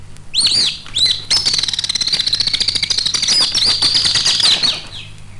Martinet Alpine (bird) Sound Effect
Download a high-quality martinet alpine (bird) sound effect.
martinet-alpine-bird.mp3